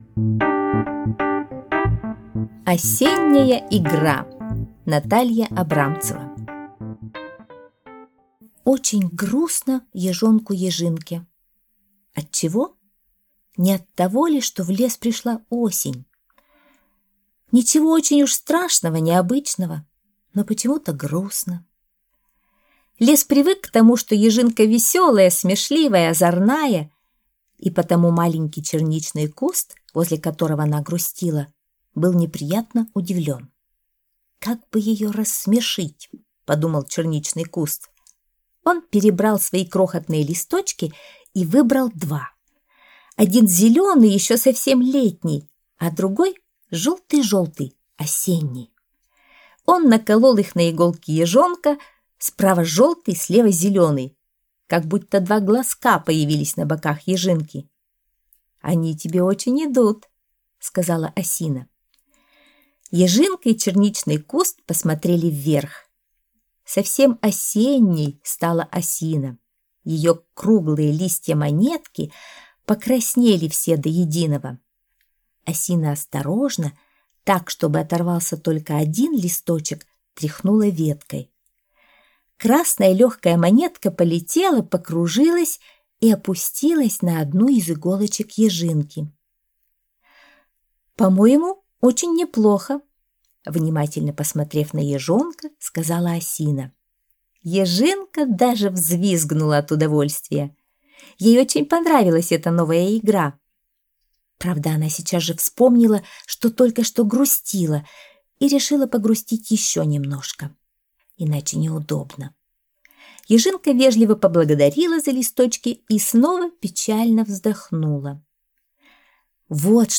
Аудиосказка «Осенняя игра»